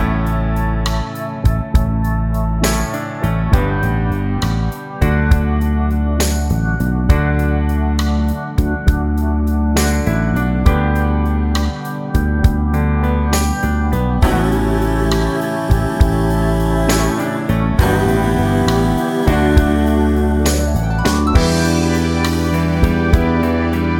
One Semitone Up Pop (1990s) 3:55 Buy £1.50